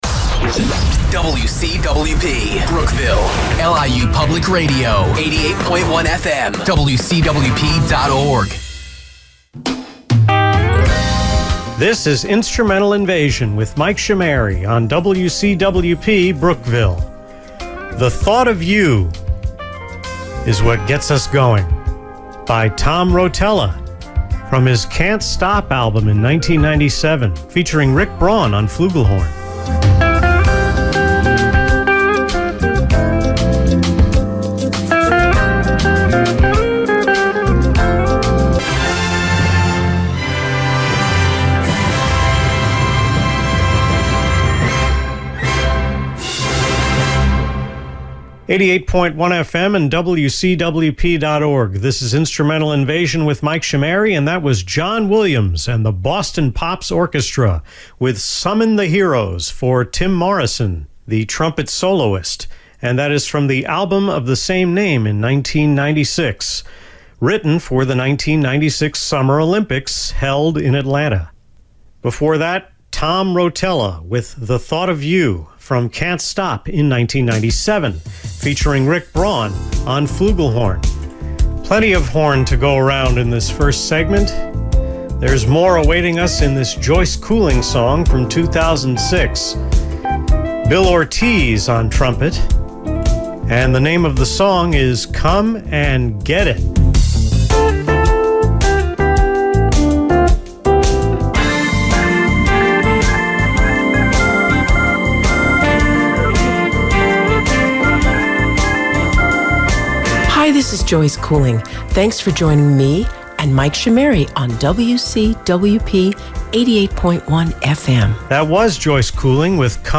Airchecks